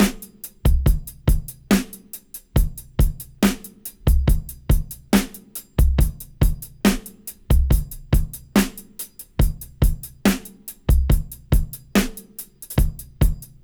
70-DRY-05.wav